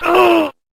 skull_death.ogg